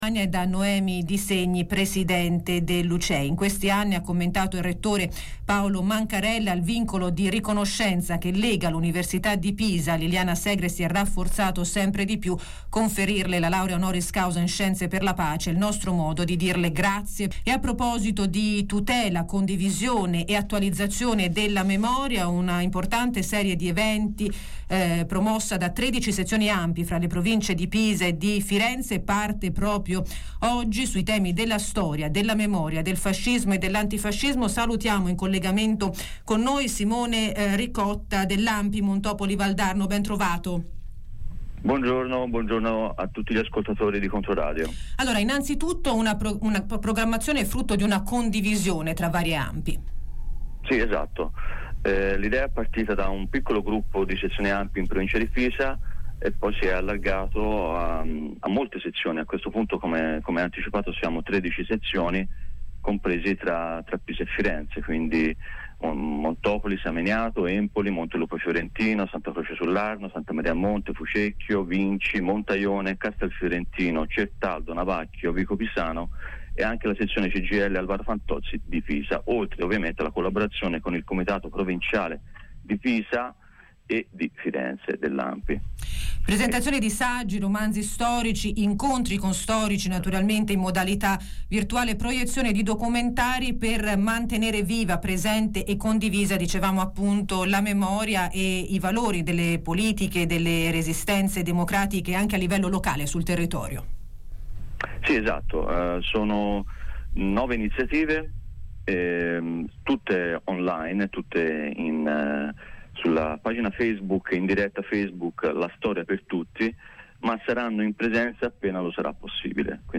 ha intervistato